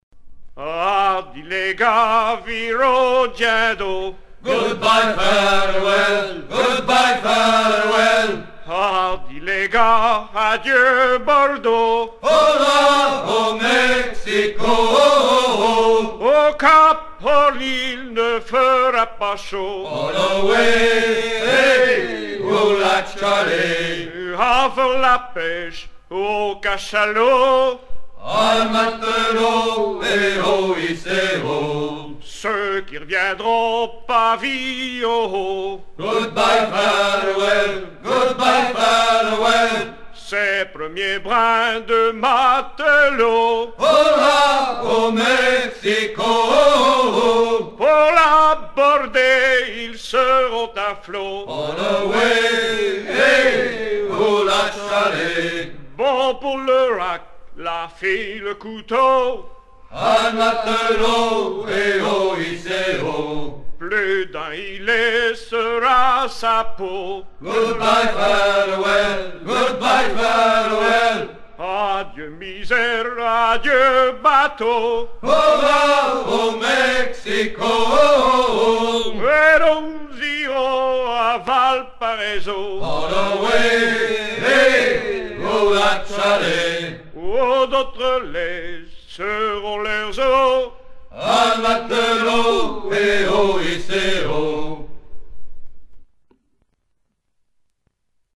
gestuel : à virer au guindeau
circonstance : maritimes ; gestuel : travail
Genre laisse
Pièce musicale éditée